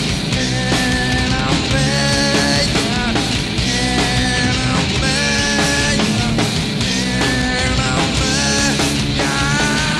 This is a soundboard recording.